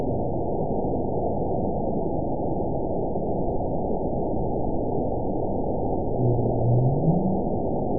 event 921761 date 12/18/24 time 22:18:58 GMT (4 months, 3 weeks ago) score 9.46 location TSS-AB02 detected by nrw target species NRW annotations +NRW Spectrogram: Frequency (kHz) vs. Time (s) audio not available .wav